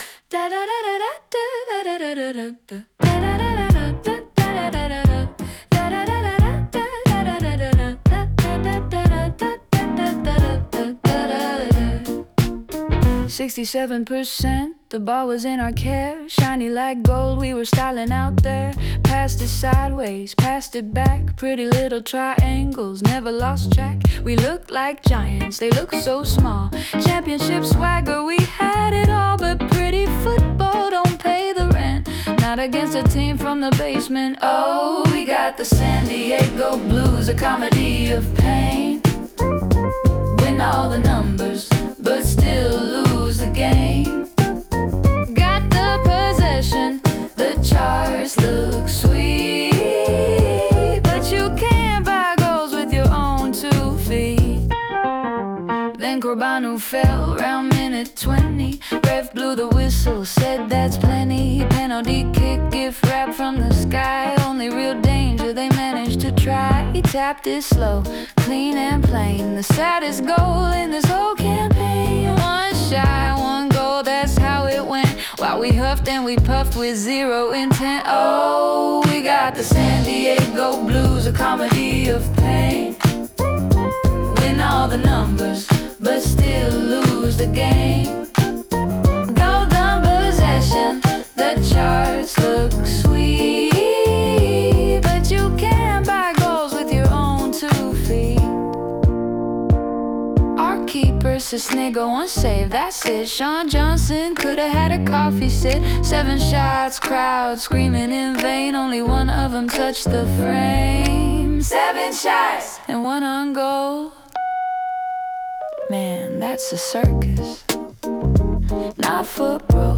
Estilo: Satírico neo-soul con toque funk, coro múltiple, humor agrio
Una pieza directa y divertida sobre la frustración estadística: ganamos los números, perdimos el resultado. El tema usa sarcasmo y un groove lento para ridiculizar la idea de “secuestrar la posesión” sin llegar al gol.